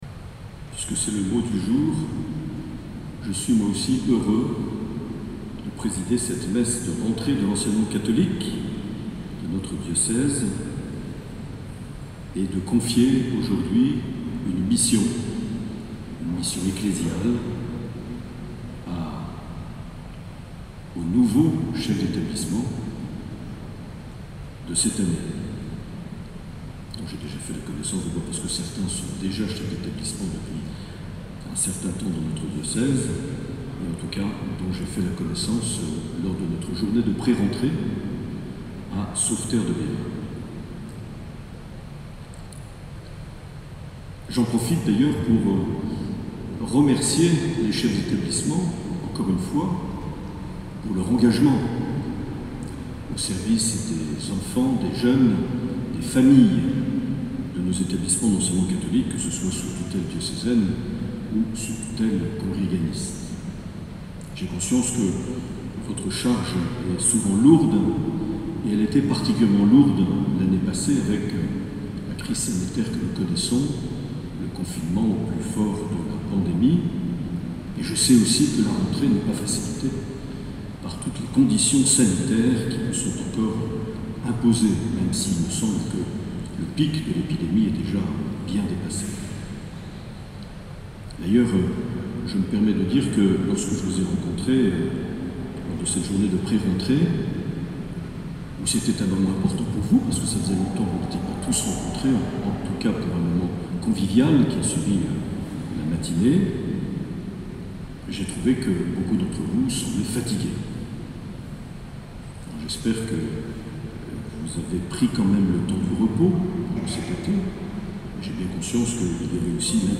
09 septembre 2020 - Cathédrale de Bayonne - Messe de rentrée de l'Enseignement Catholique
Une émission présentée par Monseigneur Marc Aillet